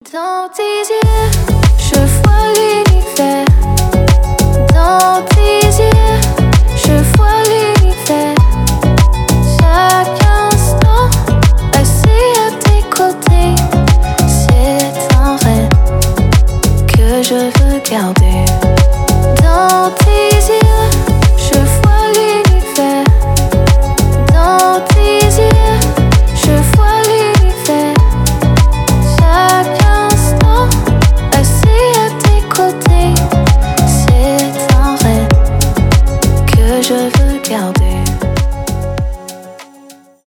deep house
поп
романтические